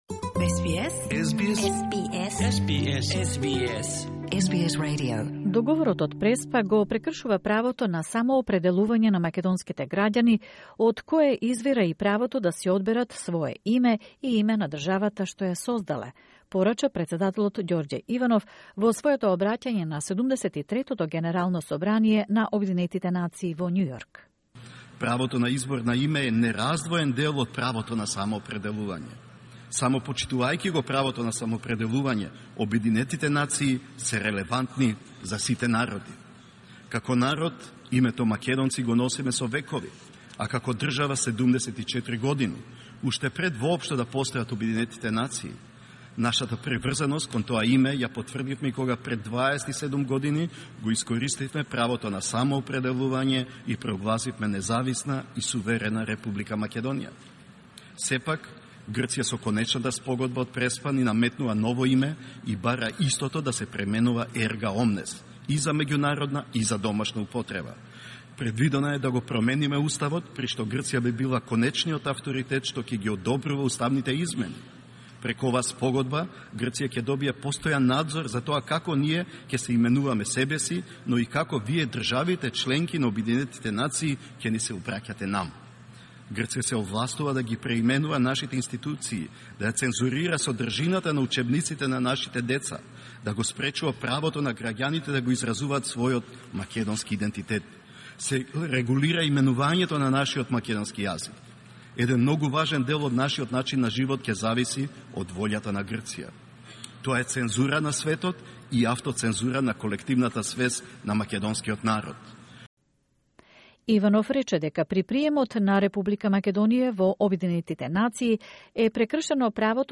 Video from the address of the Macedonian President Gjorge Ivanov at the UN General Assembly.